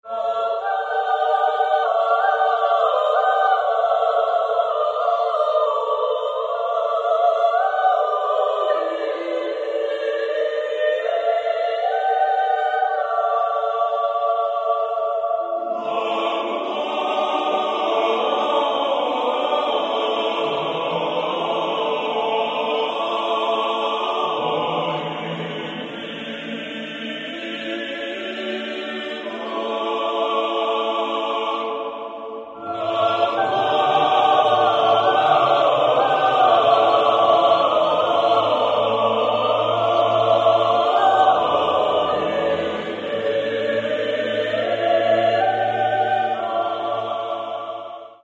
Каталог -> Классическая -> Хоровое искусство